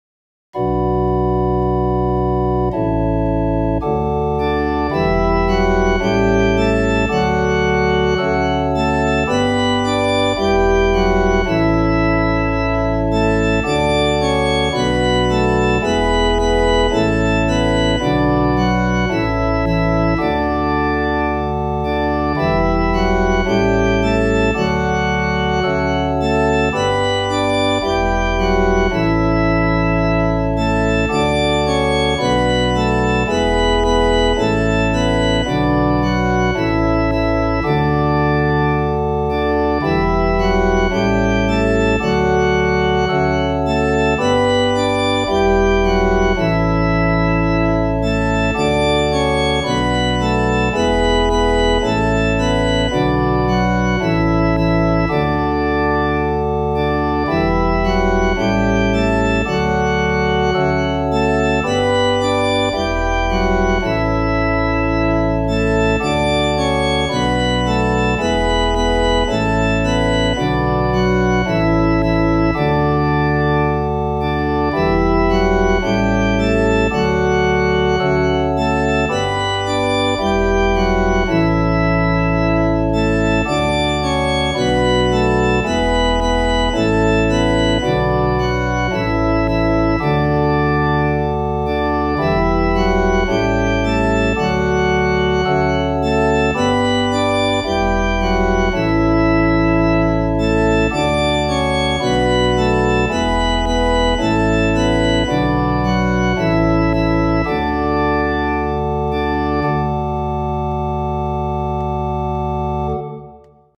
I got the chords from the ever reliable Together in Song for more fake BIAB organ.